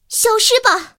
野蜂开火语音1.OGG